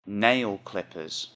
nail-clippers.mp3